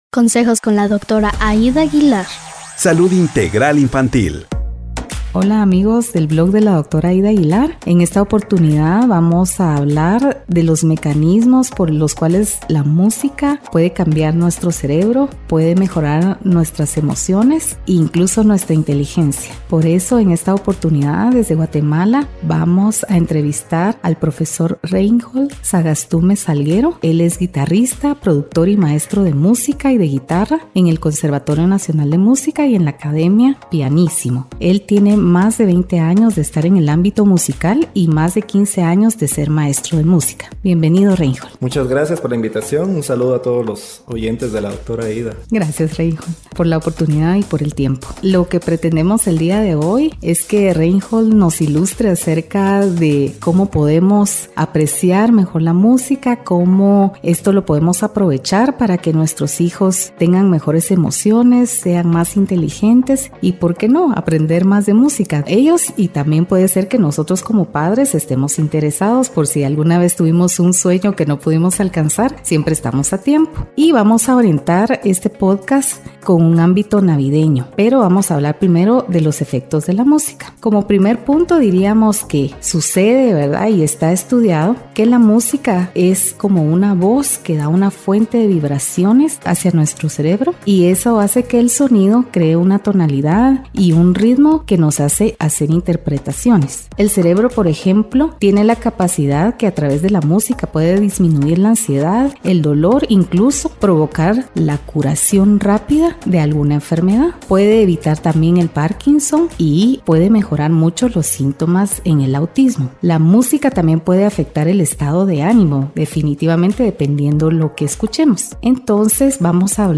Música, Navidad y el Cerebro de Nuestros Hijos. Entrevista